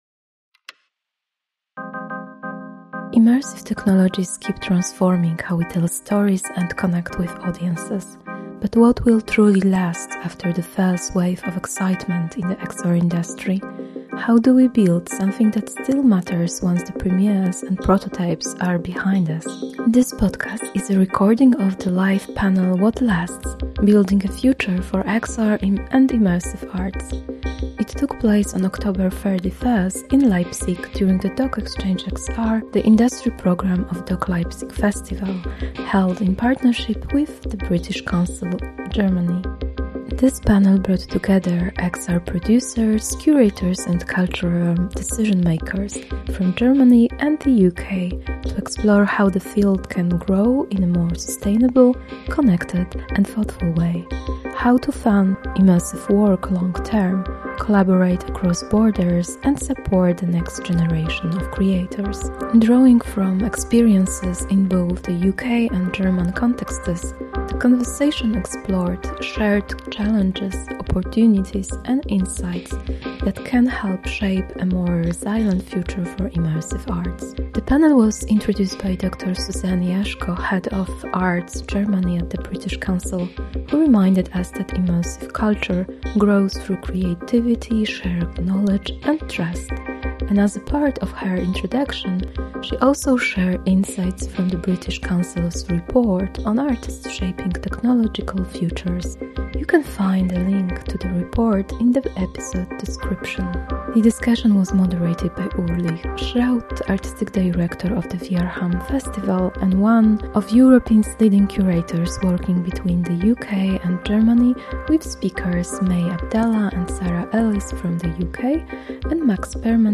Immersive media keeps evolving – but what will endure once the novelty fades? This UK–Germany conversation examines the forces shaping XR’s future, from sustainability and long-term structures to new creative practices.